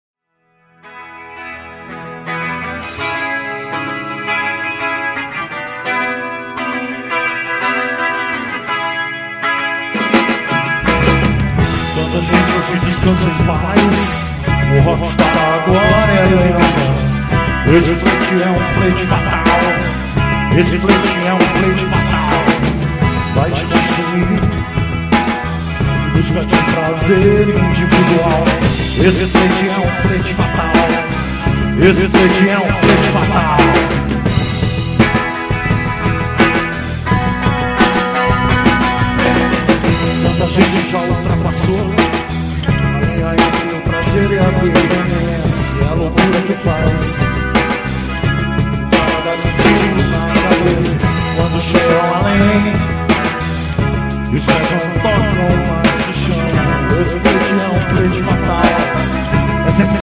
No último domingo, duas gerações do rock jacutinguense estiveram reunidas no Jacka Studio.
fizeram uma jam session.